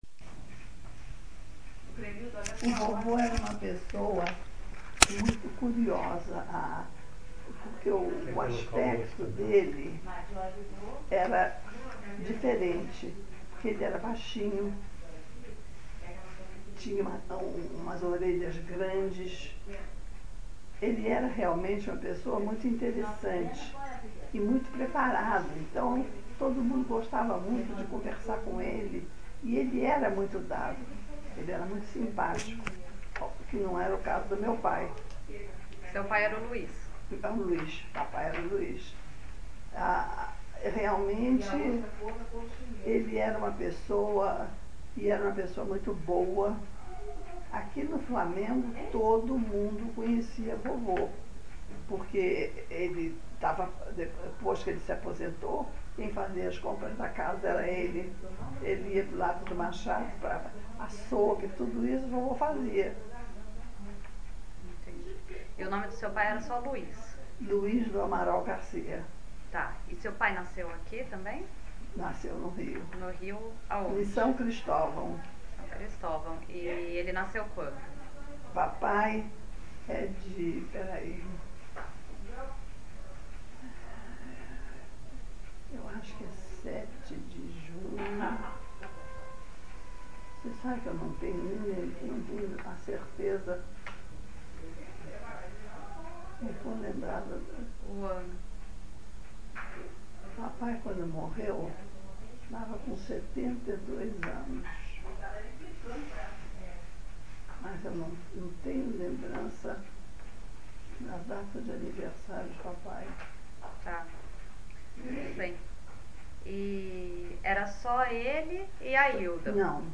Entrevista concedida a 17/8/2012 .